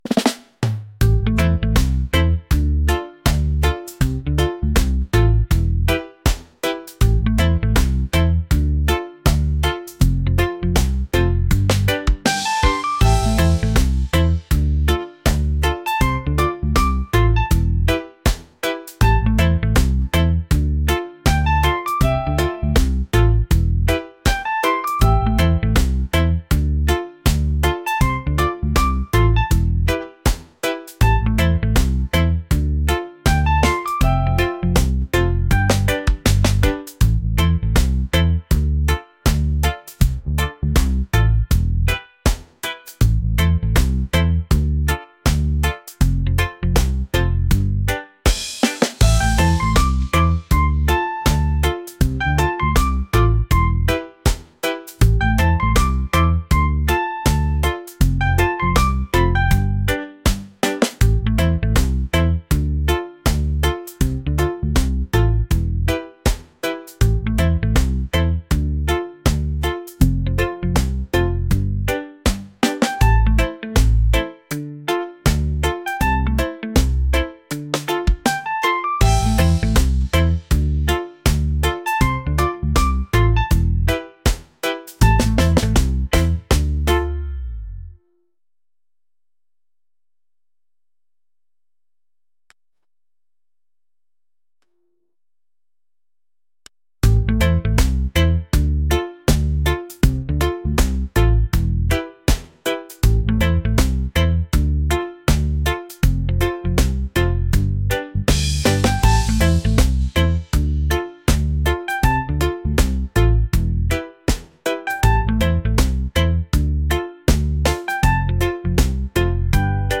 laid-back | groovy | reggae